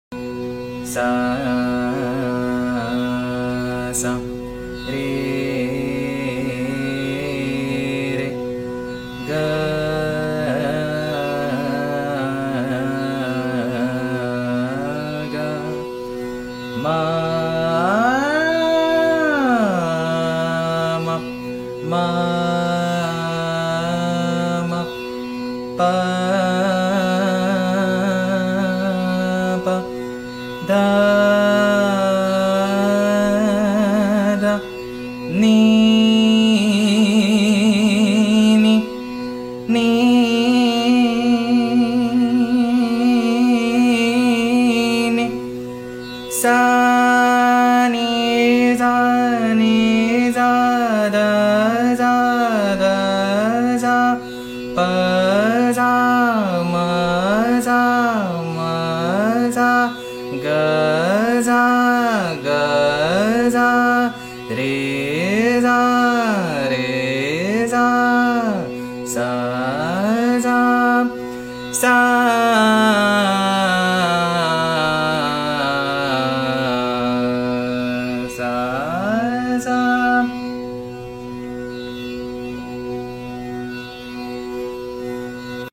This post is for exercising the vocal dynamics & shifting!